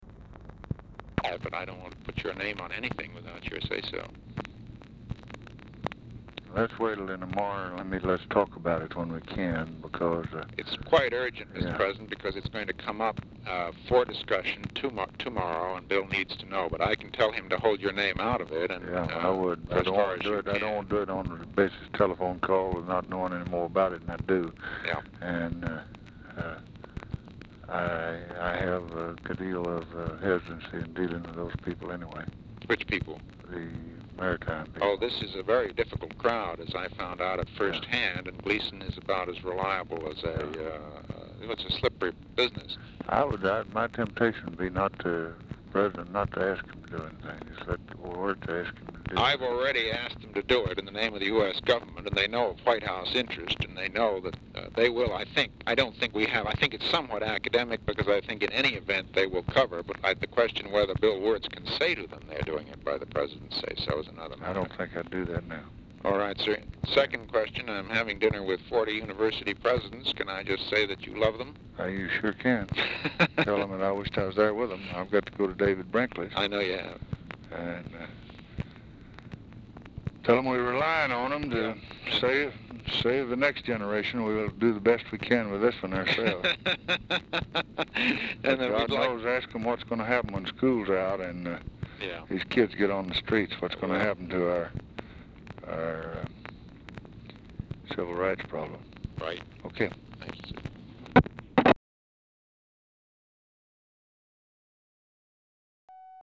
Telephone conversation # 3197, sound recording, LBJ and MCGEORGE BUNDY, 4/29/1964, 6:32PM | Discover LBJ
Format Dictation belt
Location Of Speaker 1 Oval Office or unknown location